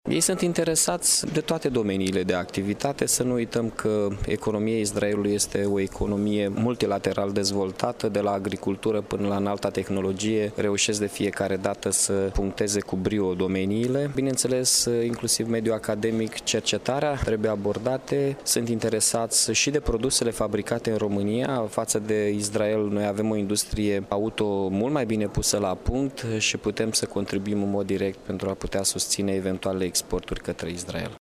Şi primarul Iaşului, Mihai Chirica, a insistat asupra dezvoltării relaţiilor comerciale cu localităţi importante din Israel: